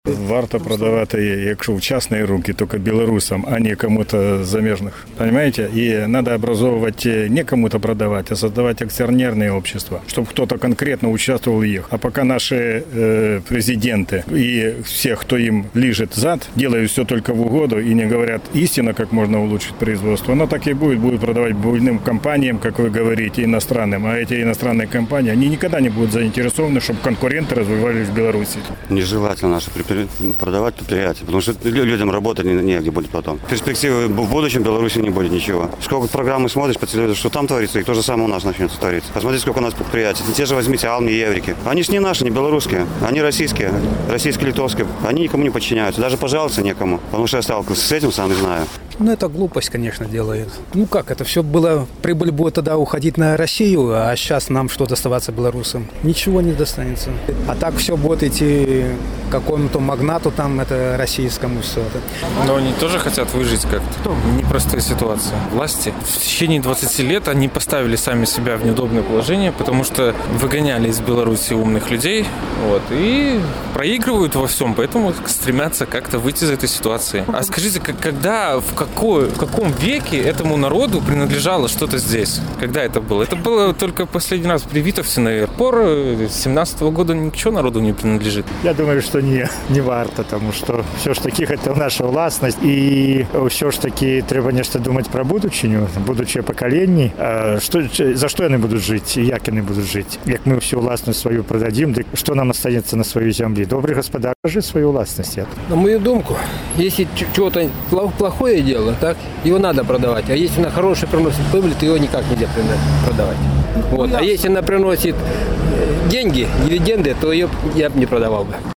З такім пытаньнем наш карэспандэнт зьвяртаўся да гарадзенцаў.